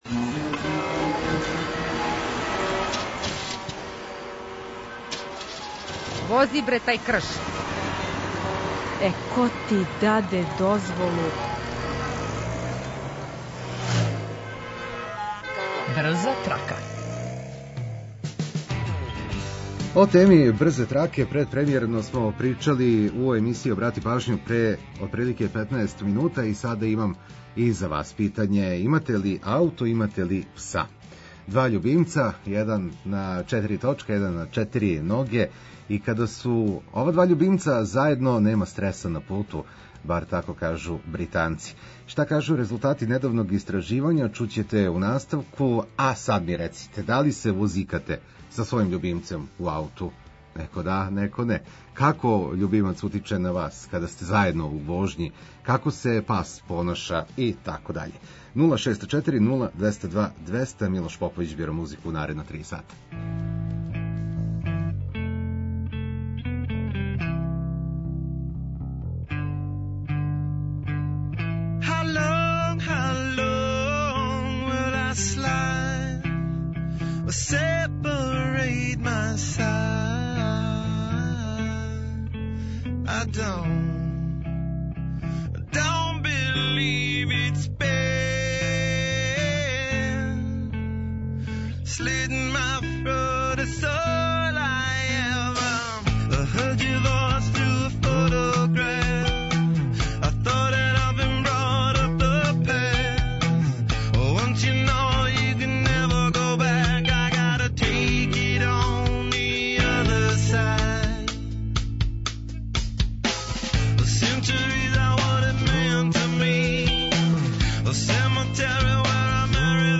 Наша репортерка је у Београду и прича нам о животу у главном граду.